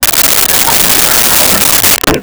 Crowd Laughing 03
Crowd Laughing 03.wav